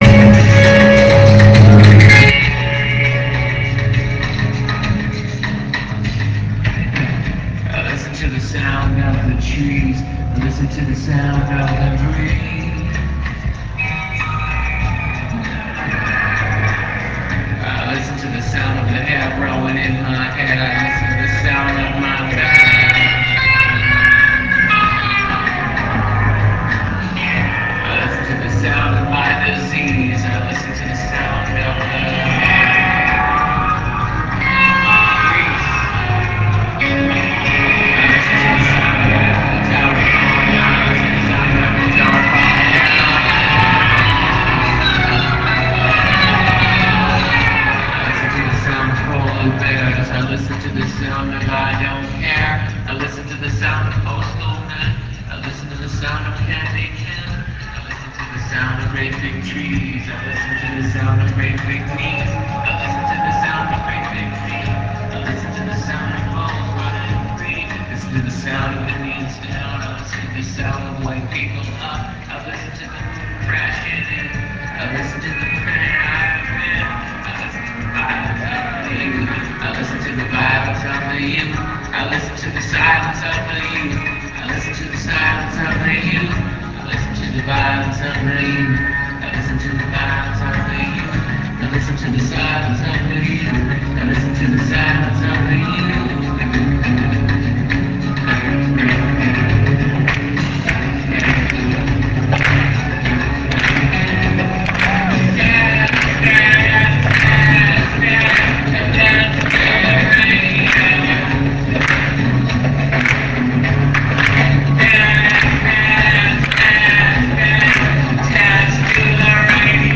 Palais Des Sports; Paris, France